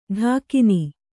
♪ ḍhākini